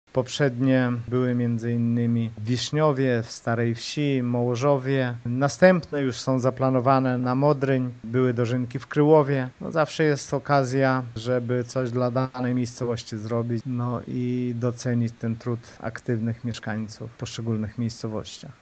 Zgodnie z tradycją, co roku "święto plonów" jest organizowane w innej miejscowości, tym razem dożynki odbędą się w Mirczu - informuje wójt Lech Szopiński: